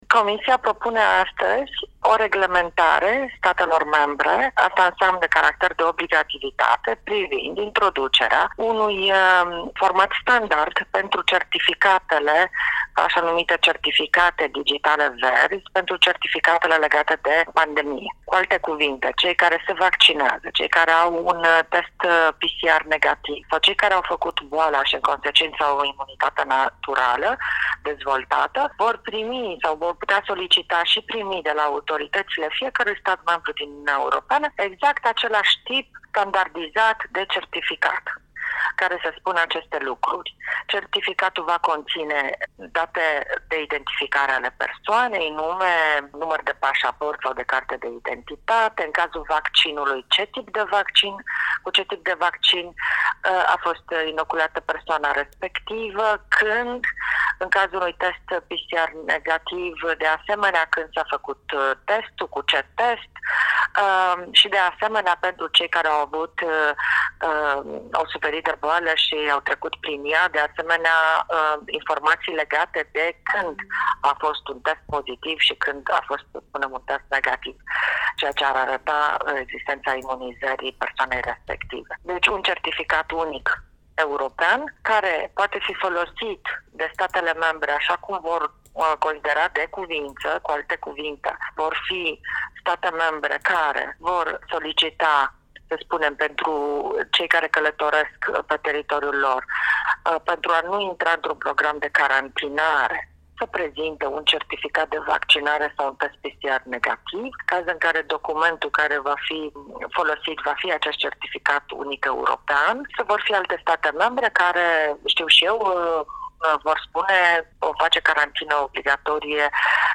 Inteviul integral cu comisarul european Adina Vălean:
interviu-Adina-Valean-integral-site.mp3